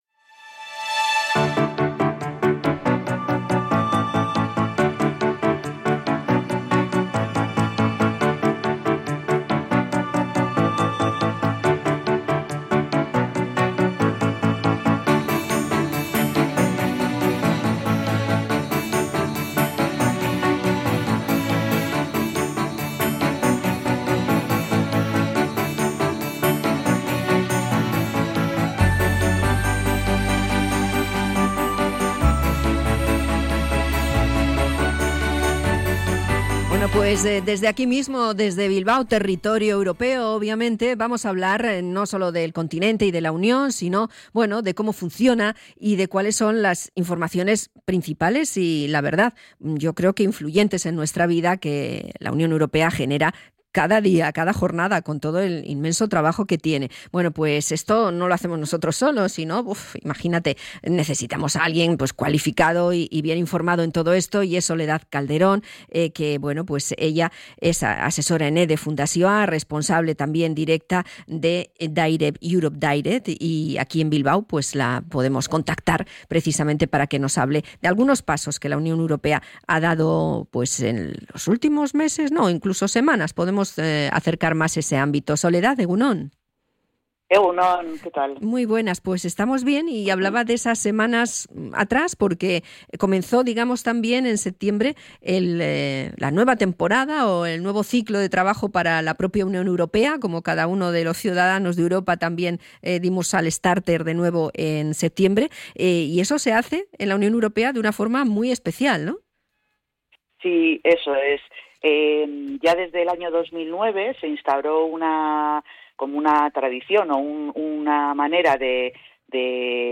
Visita mensual a la radio